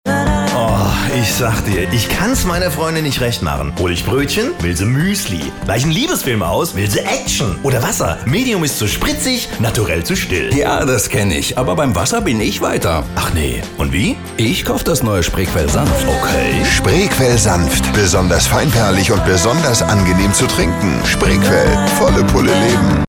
Sonore, warme, sympathische, energetische und glaubwürdige Bass-Bariton-Stimme
Sprechprobe: Industrie (Muttersprache):